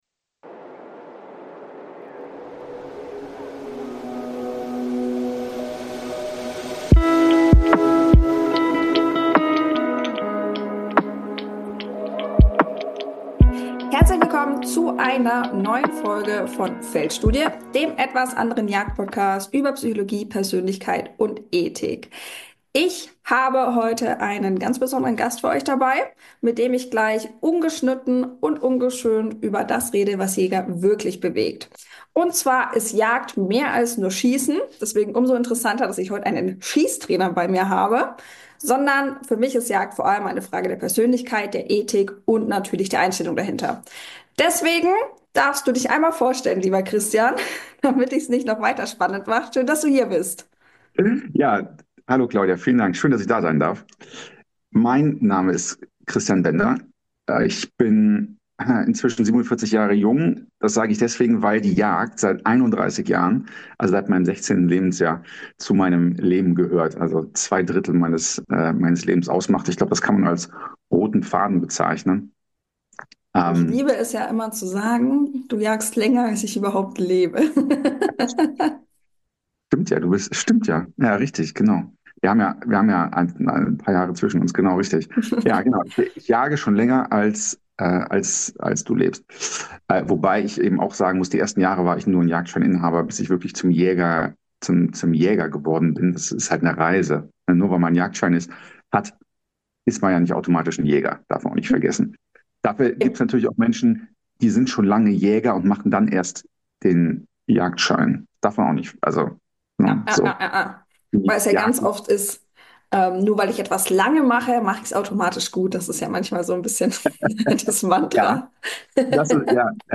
Ein tiefgründiges Gespräch...